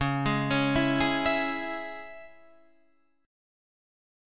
C#m chord